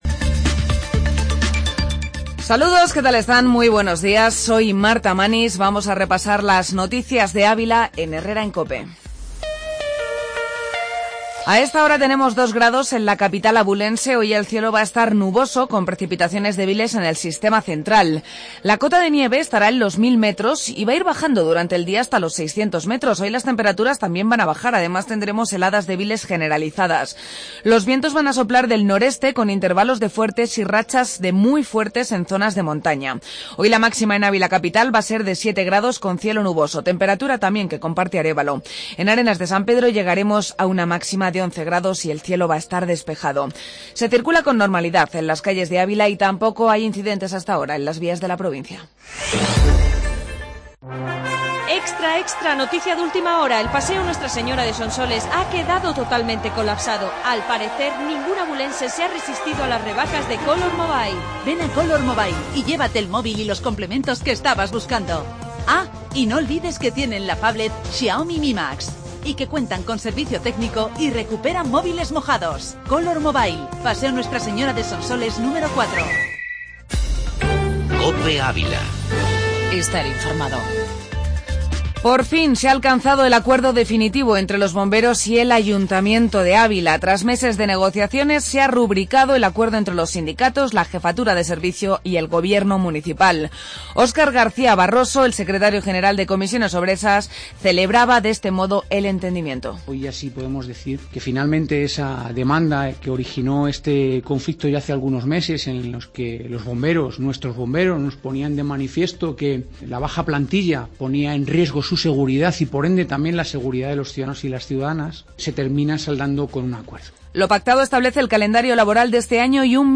Informativo La Mañana en Ávila